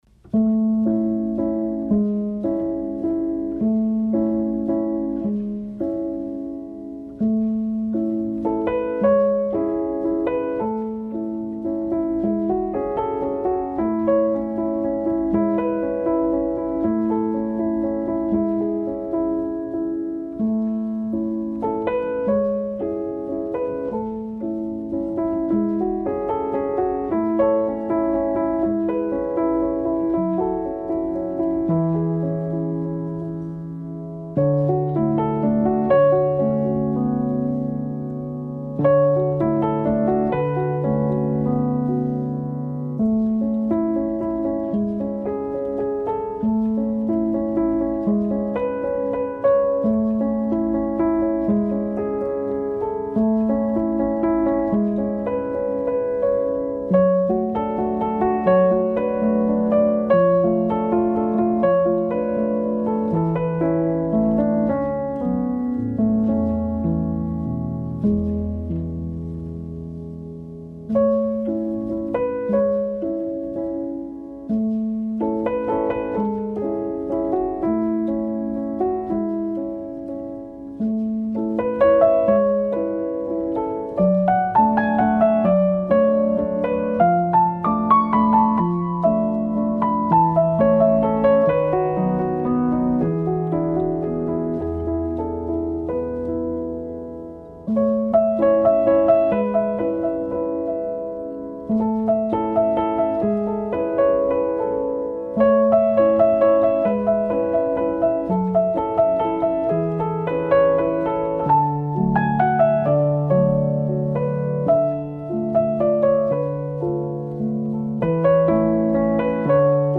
موسیقی بی کلام آرامبخش موسیقی بی کلام پیانو